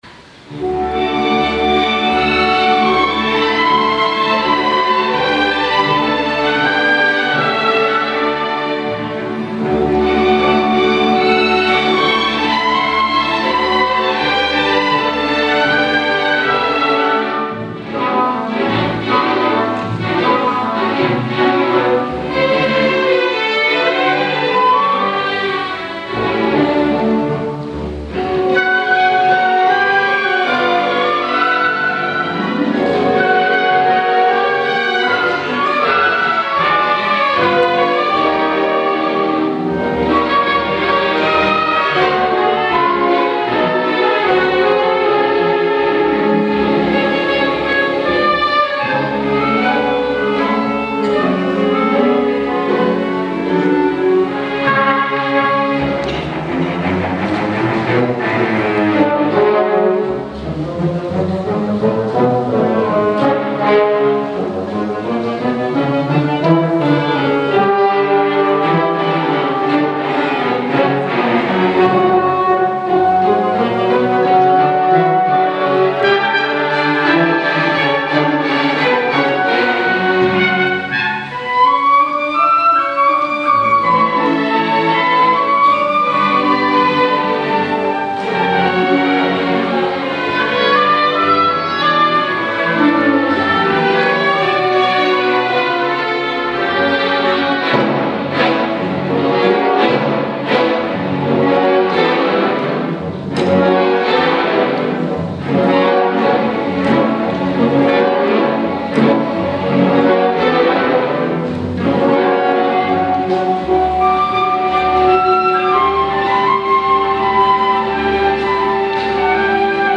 20h - Aula Magna, Louvain-la-Neuve
Enfin, vous pourrez écouter la suite n°2 du Tricorne de Manuel de Falla, reflétant toute la vigueur et le dynamisme hispaniques.